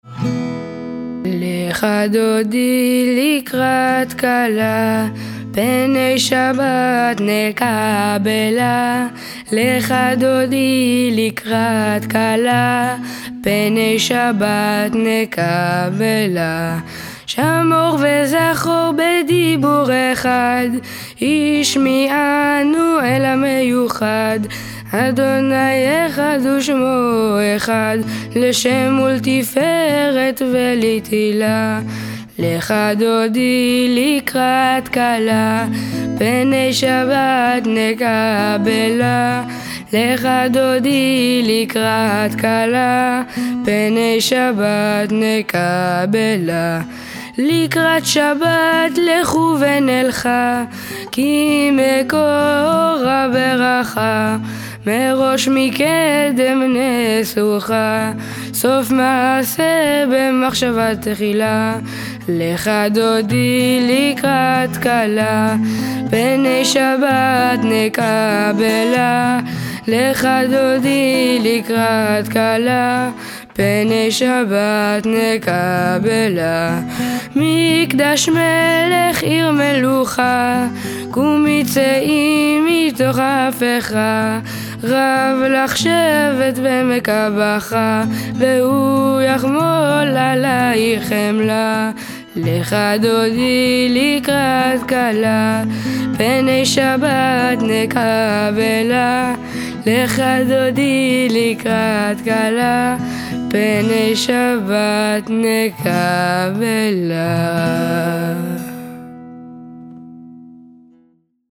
Audio Enfants: